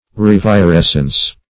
Search Result for " revirescence" : The Collaborative International Dictionary of English v.0.48: Revirescence \Rev`i*res"cence\, n. [L. revirescens, p. pr. of revirescere to grow green again.] A growing green or fresh again; renewal of youth or vigor.